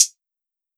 Closed Hats
hihat 5.wav